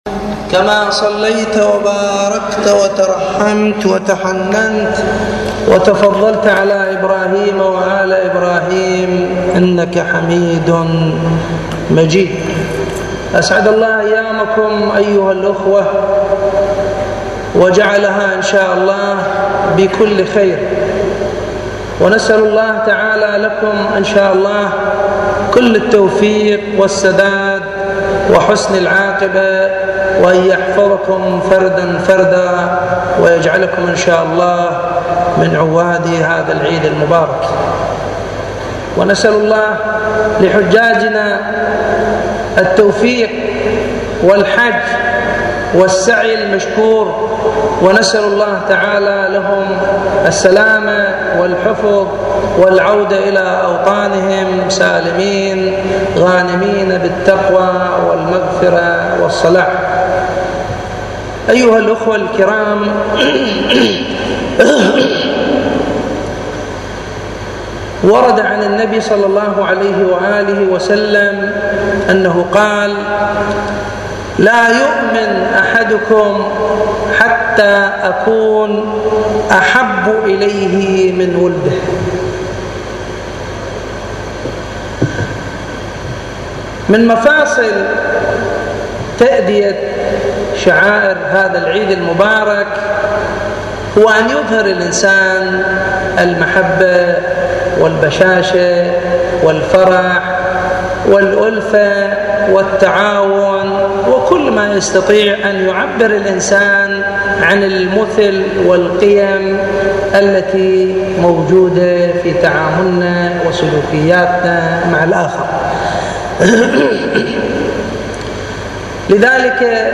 صلاة عيد الاضحى المبارك في الناصرية - تقرير صوتي مصور -